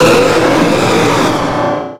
Cri de Méga-Rayquaza dans Pokémon Rubis Oméga et Saphir Alpha.
Cri_0384_Méga_ROSA.ogg